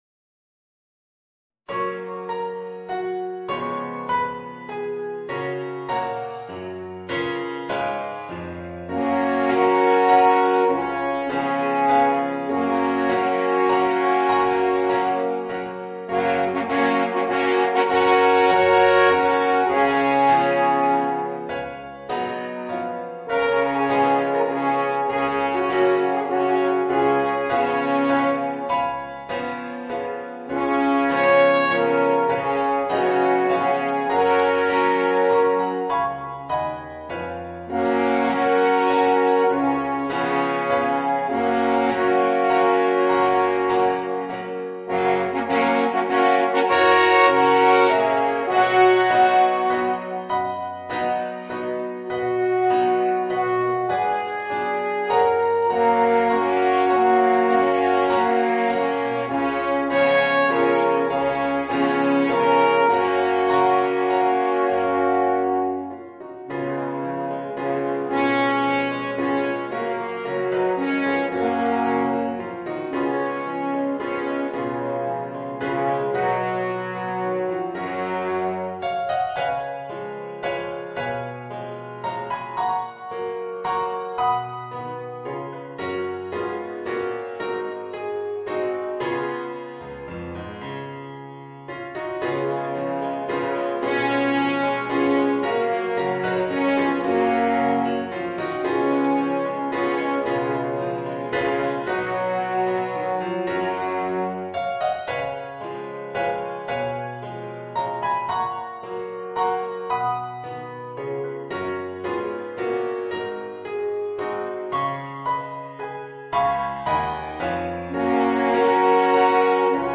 Voicing: 2 Alphorns